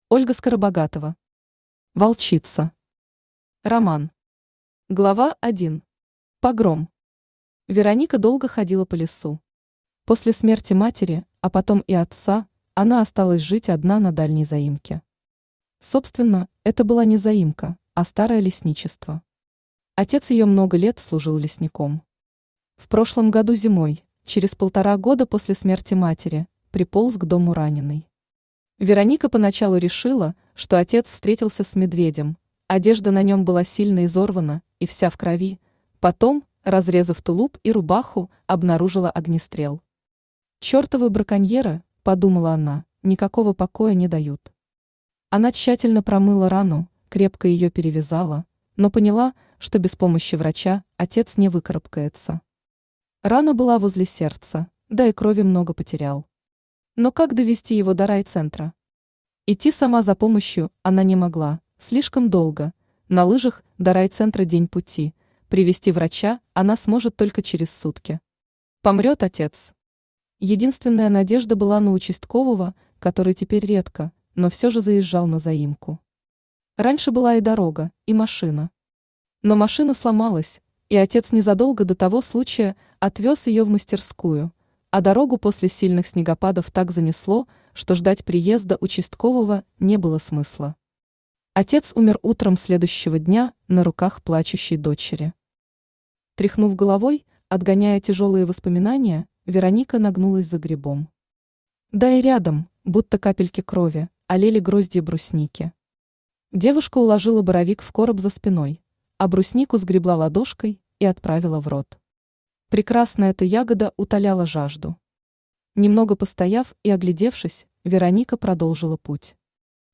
Аудиокнига Волчица | Библиотека аудиокниг
Aудиокнига Волчица Автор Ольга Александровна Скоробогатова Читает аудиокнигу Авточтец ЛитРес.